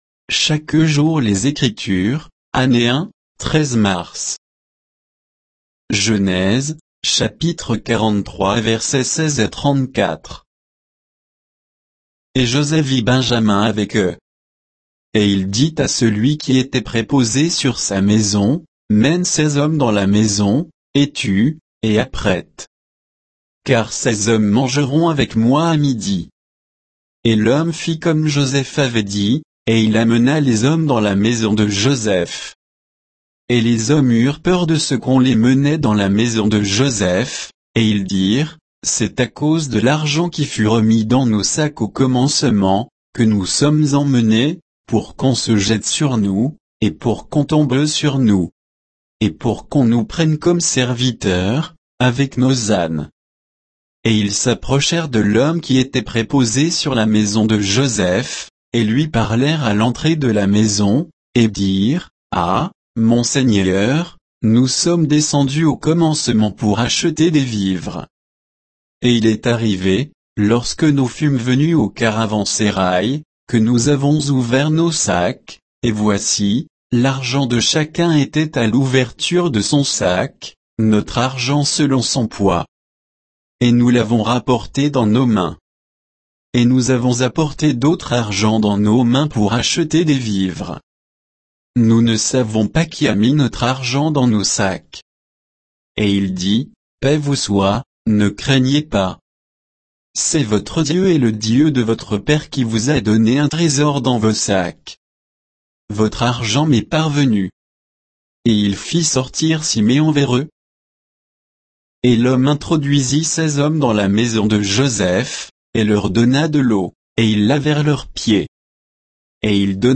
Méditation quoditienne de Chaque jour les Écritures sur Genèse 43